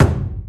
dong.ogg